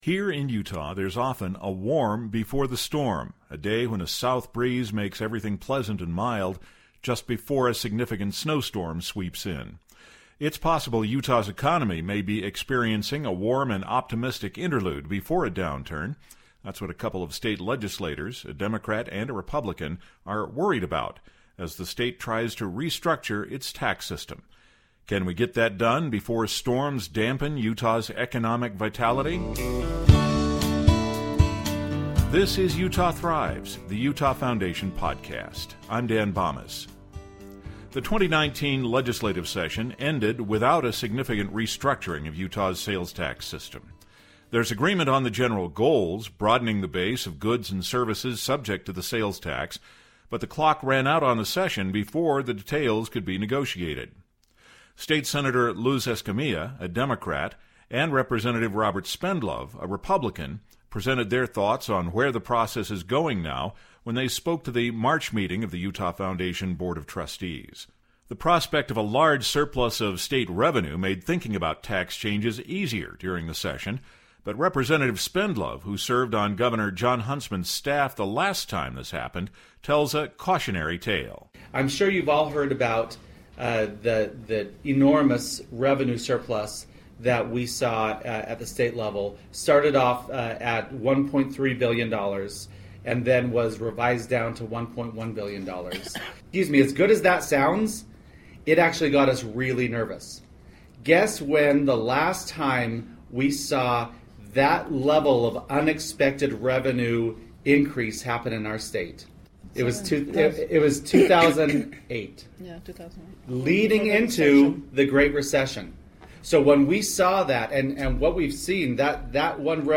State Senator Luz Escamilla, a Democrat, and Representative Robert Spendlove, a Republican, presented their thoughts on where the process is going now when they spoke to the March meeting of the Utah Foundation Board of Trustees.